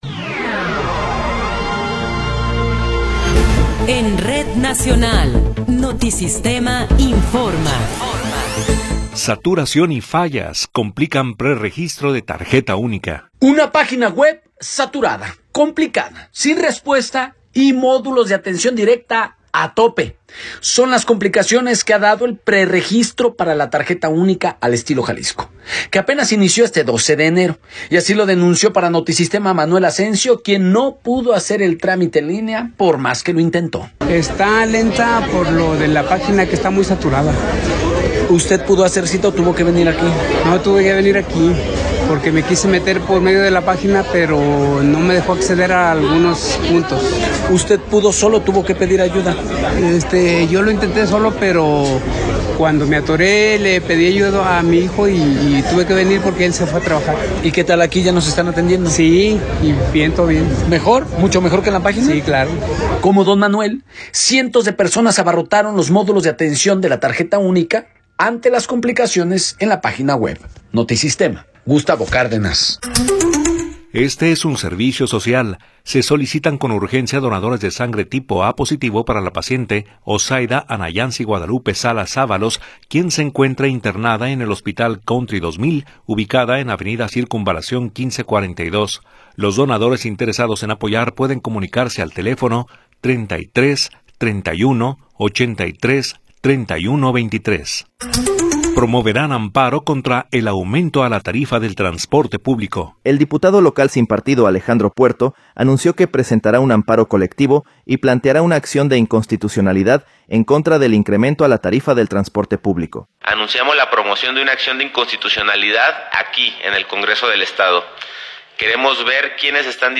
Noticiero 18 hrs. – 13 de Enero de 2026